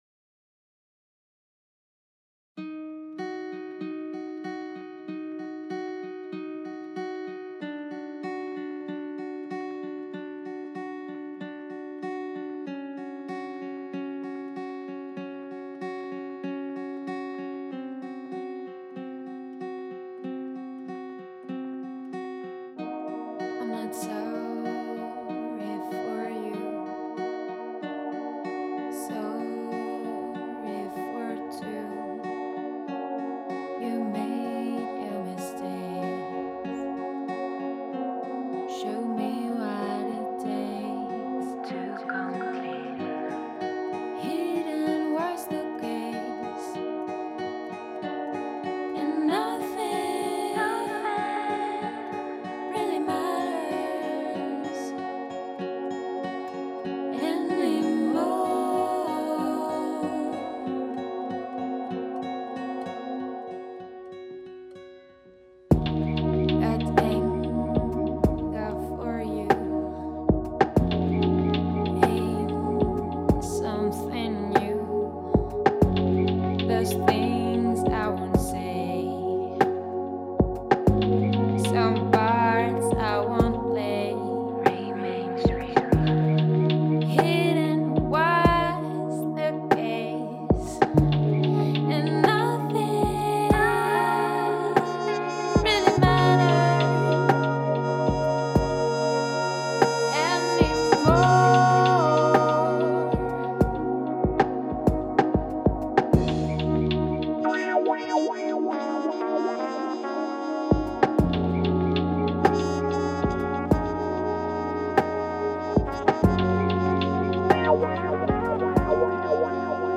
Évocations cinématographiques, trip hop jazzy
invitent à la contemplation, au calme et à la volupté.